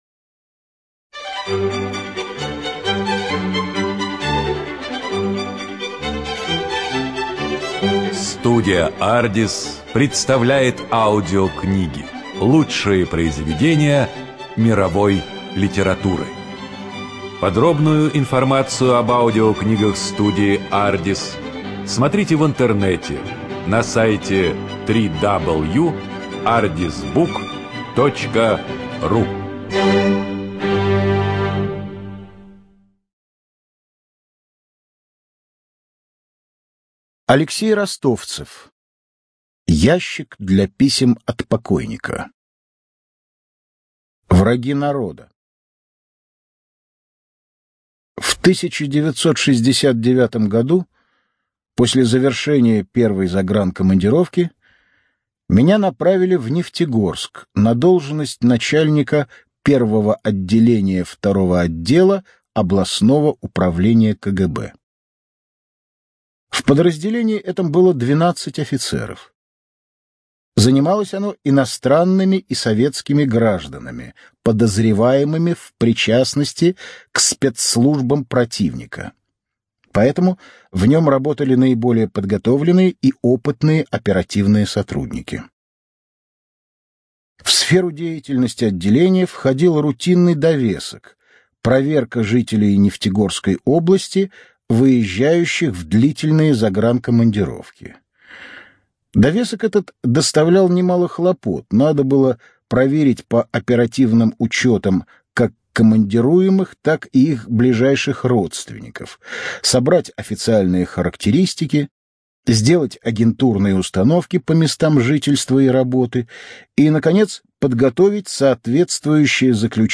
Студия звукозаписиАрдис